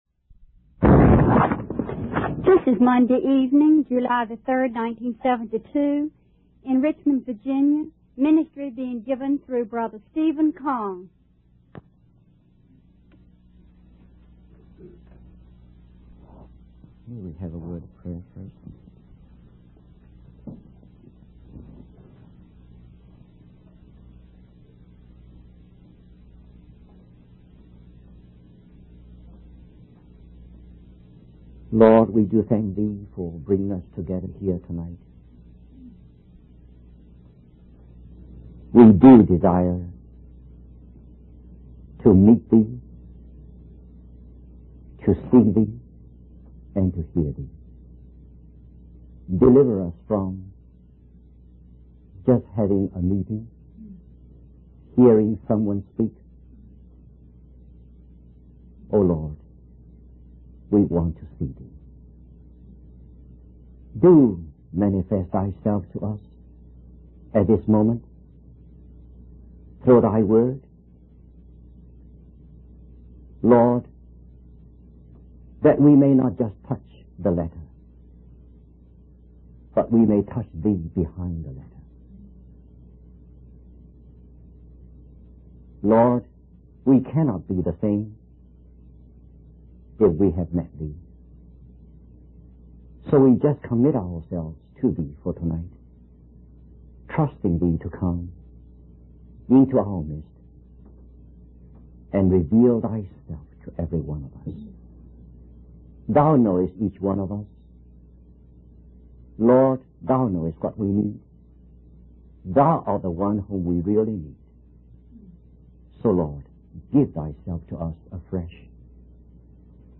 In this sermon, the preacher emphasizes the importance of understanding the character of Jesus as the servant of the Lord.